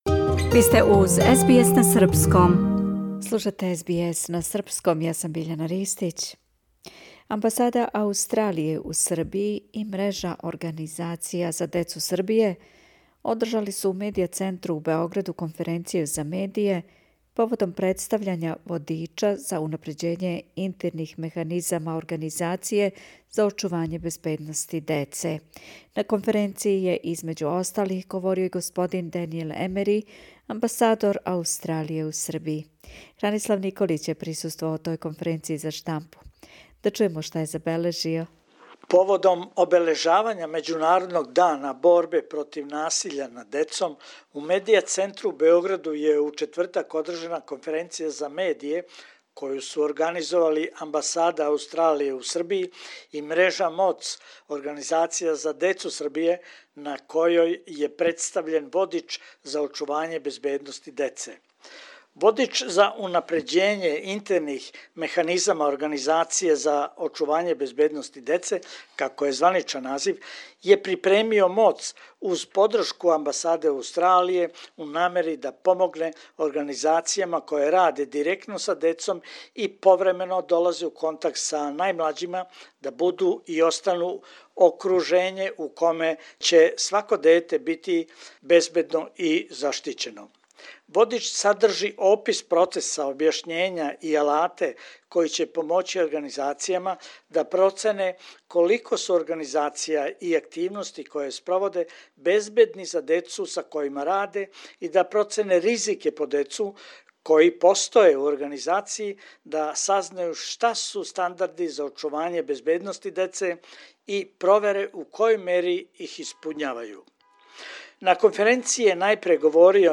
World day for prevention of child abuse media conference in Belgrade Source: Media Centre Belgrade
На конференцији је између осталих говорио и господин Данијел Емери (Daniel Emery), Амбасадор Аустралије у Србији.